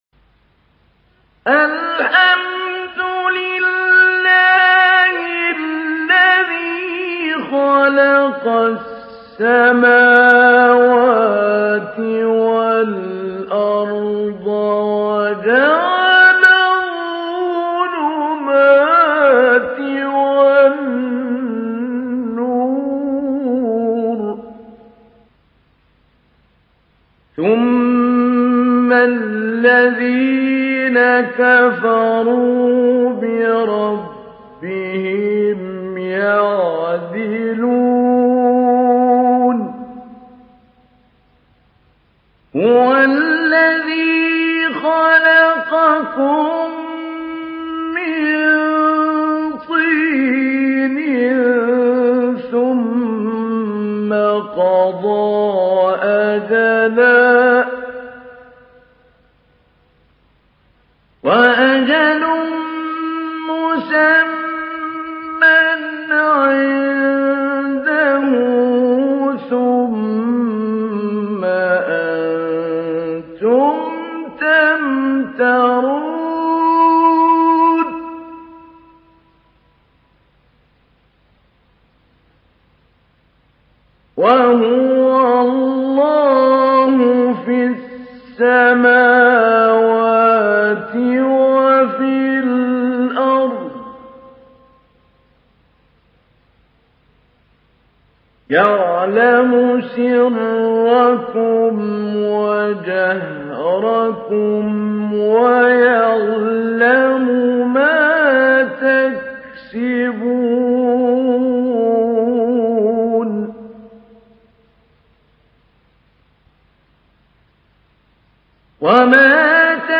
تحميل : 6. سورة الأنعام / القارئ محمود علي البنا / القرآن الكريم / موقع يا حسين